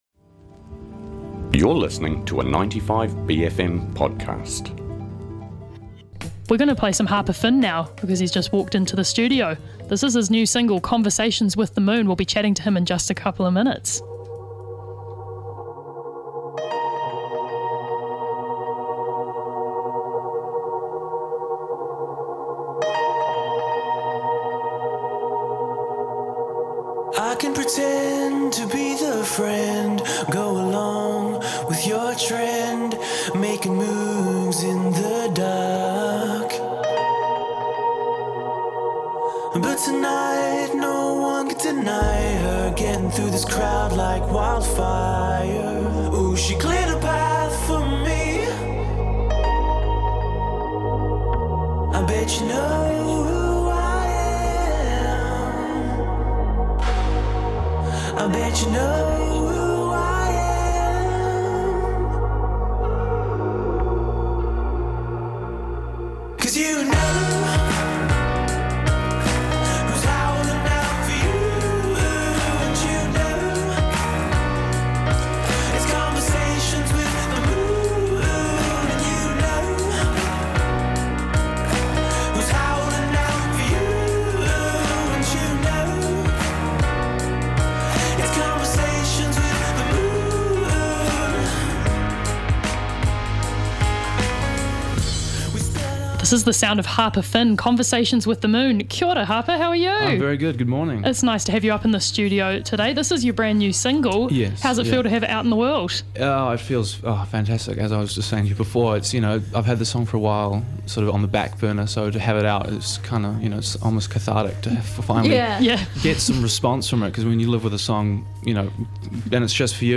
pops up to the studio to chat about his newly released singles.